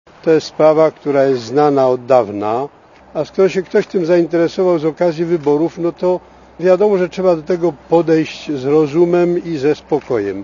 Mówi biskup Tadeusz Pieronek